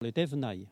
Localisation Perrier (Le)
Catégorie Locution